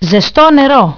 Hot water Ζεστό νερό zεstό nεrό